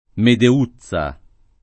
Medeuzza [ mede 2ZZ a ]